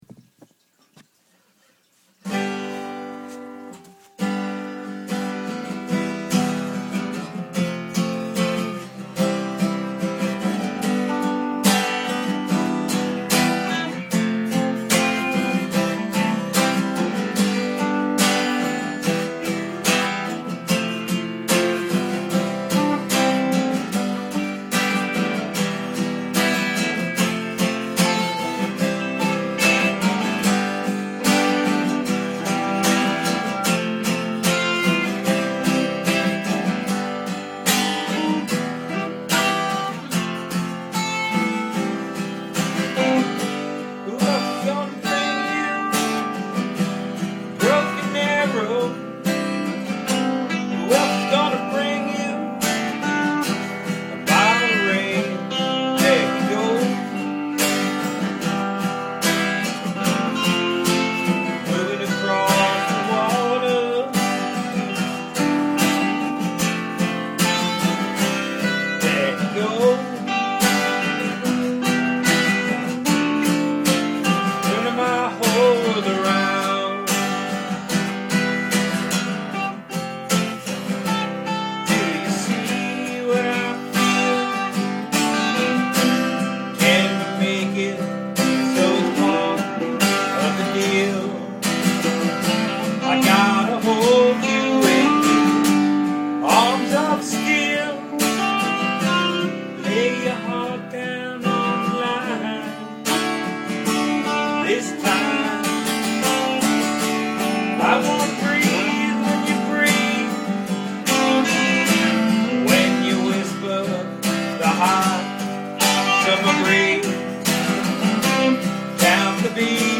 9:30 Club - Philly Jam Band Rehearsals